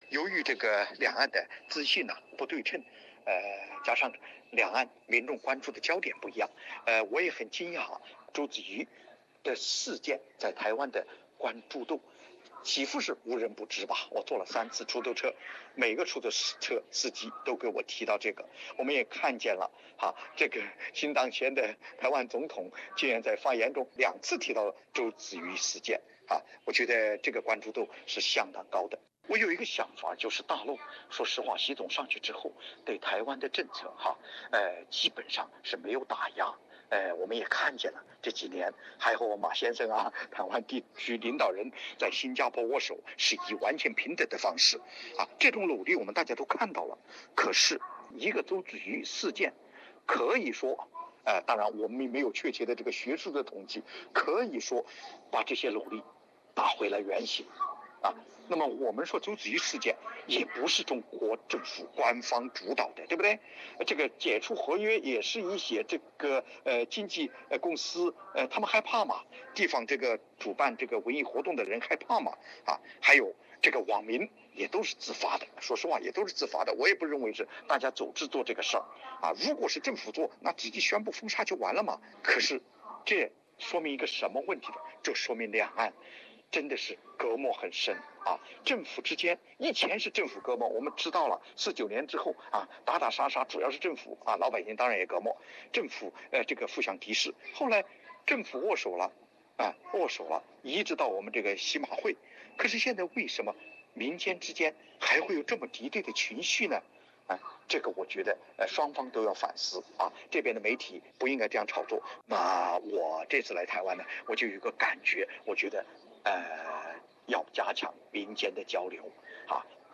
他呼吁两岸加强更多更深层次的民间交流。 请听采访录音。